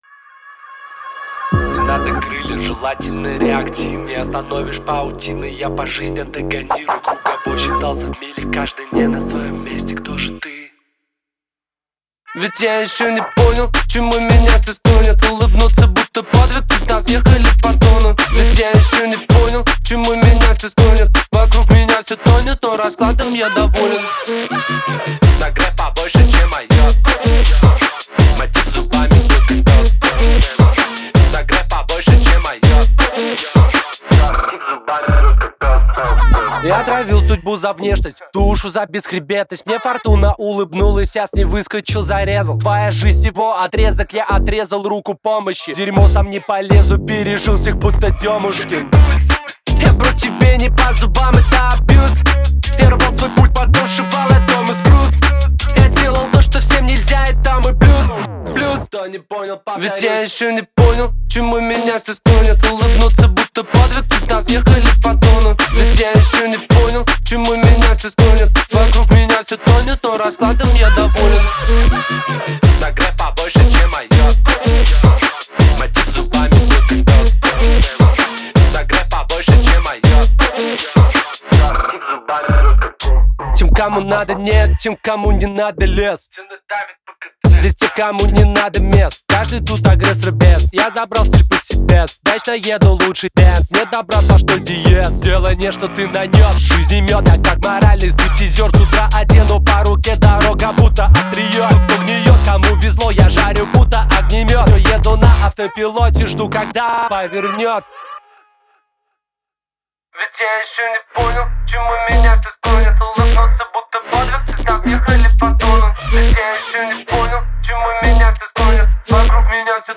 Рэп, 2026